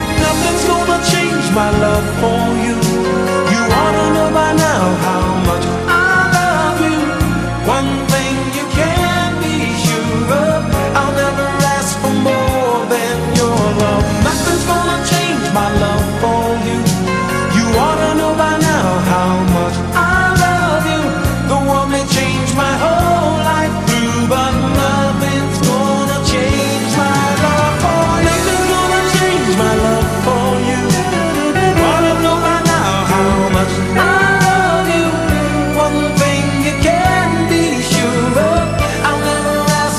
ретро , 80-е
романтические
поп , соул